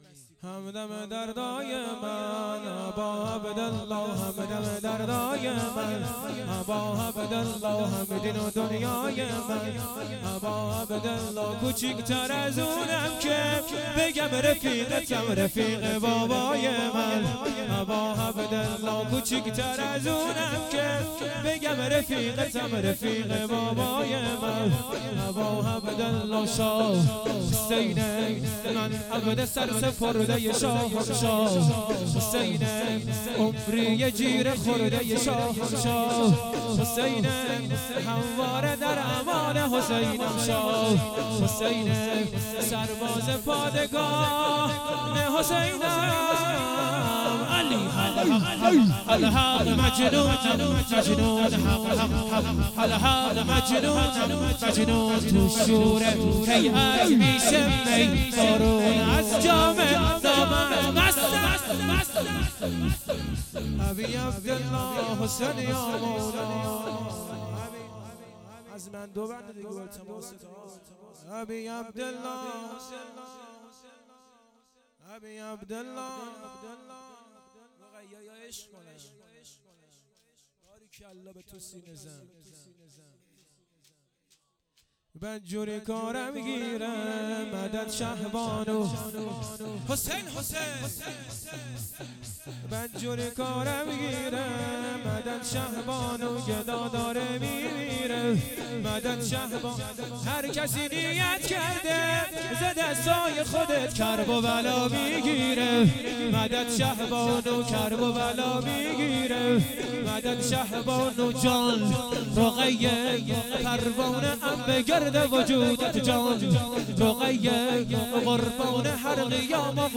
هیئت شیفتگان جوادالائمه علیه السلام مشهد الرضا
شور
شهادت حضرت زهرا ۷۵ روز ۱۳۹۸